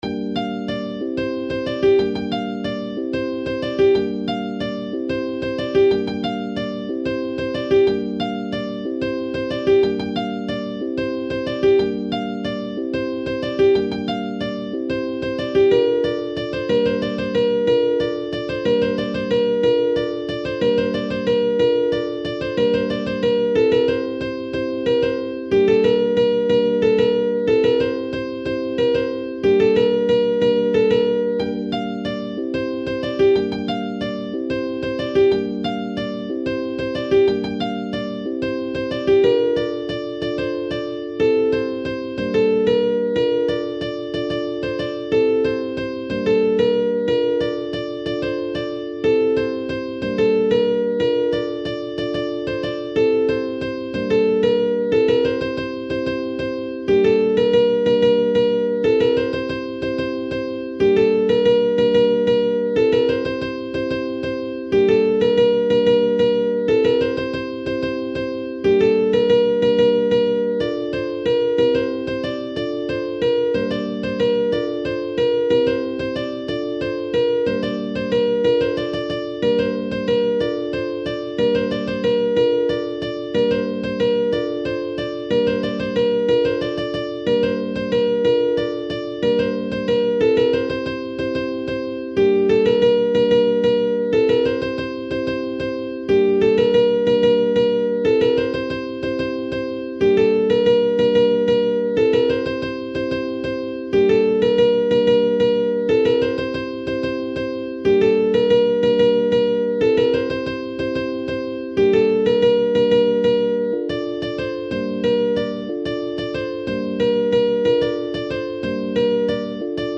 محلی جنوبی